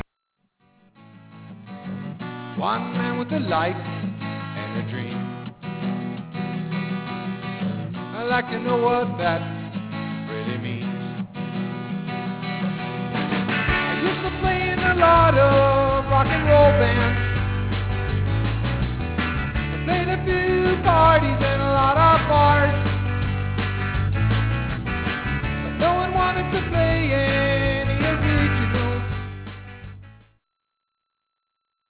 up in a folk song!